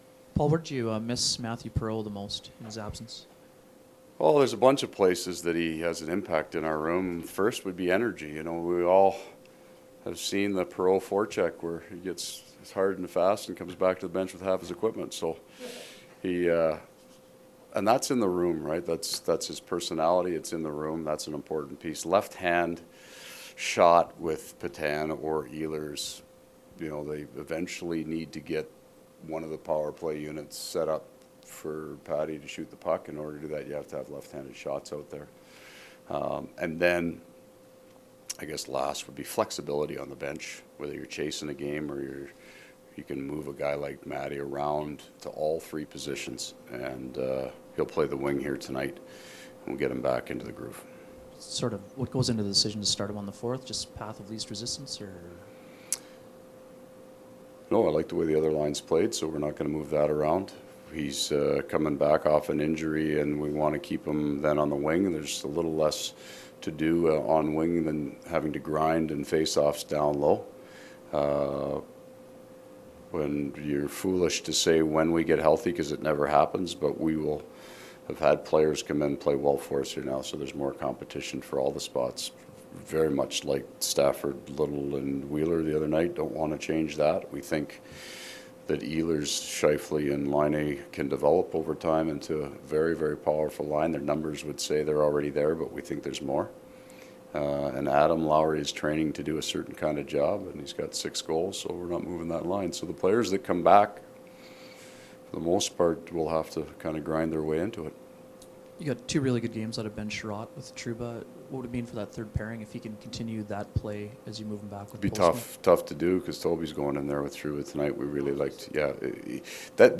Coach pre-game audio:
All audio courtesy of TSN 1290 Winnipeg.